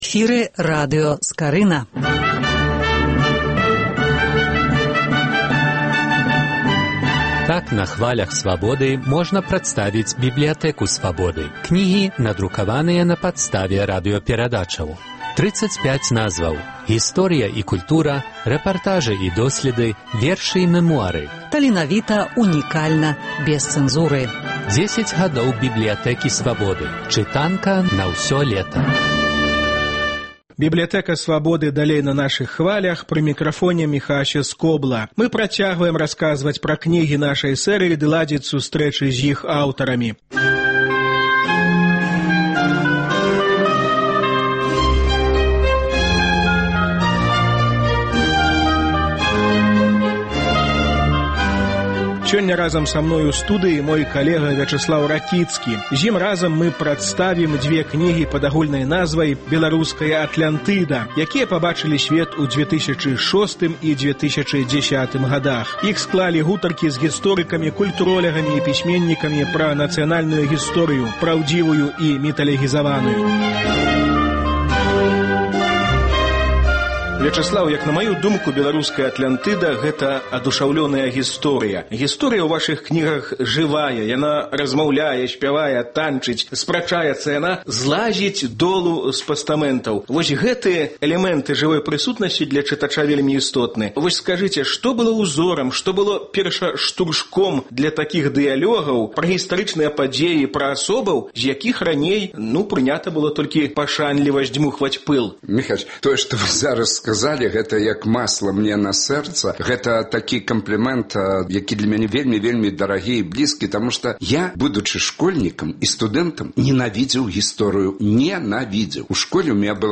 Працяг радыёсэрыі “10 гадоў “Бібліятэкі Свабоды”.